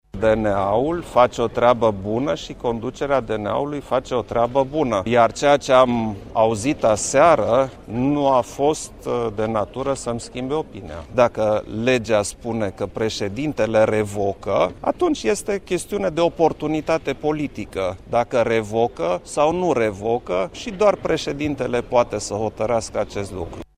În pofida acestor acuzaţii, preşedintele României, Klaus Iohannis, a reiterat, astăzi, la Bruxelles, că nu există motive întemeiate pentru revocarea Codruţei Kovesi:
Iohannis-DNA.mp3